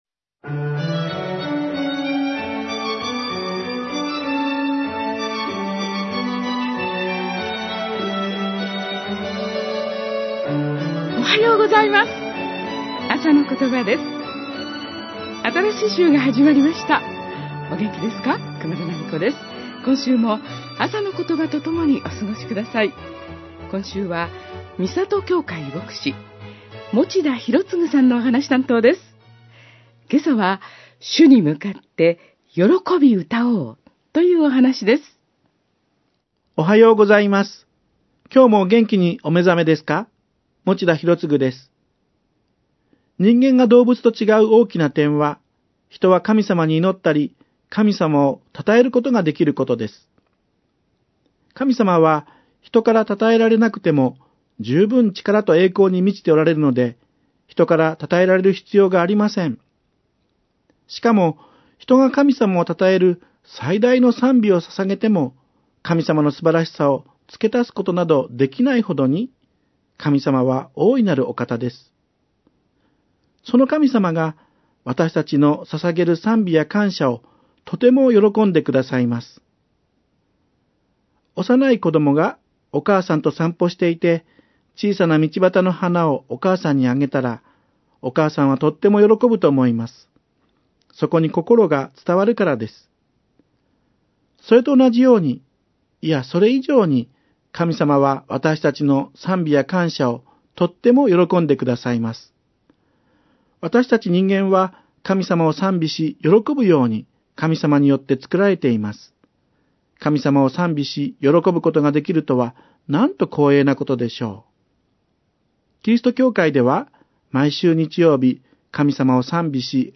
メッセージ